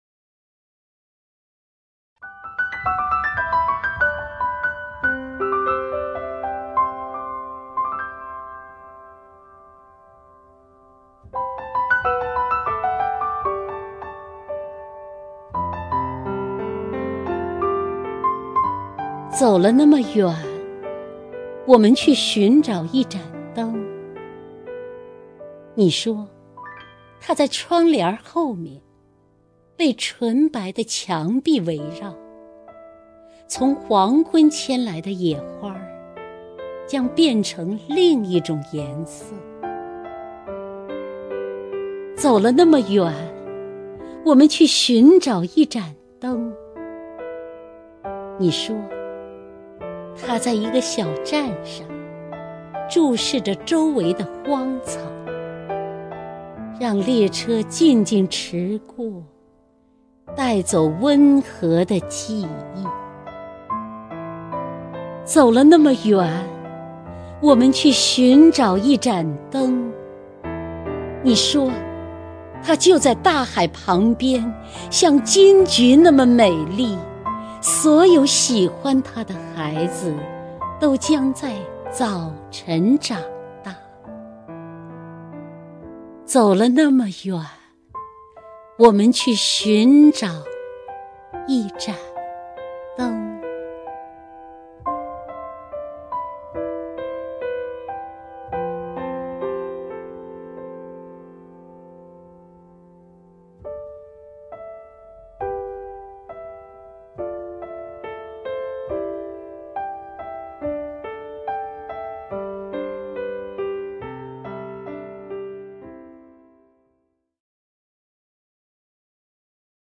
首页 视听 名家朗诵欣赏 姚锡娟
姚锡娟朗诵：《我们去寻找一盏灯》(顾城)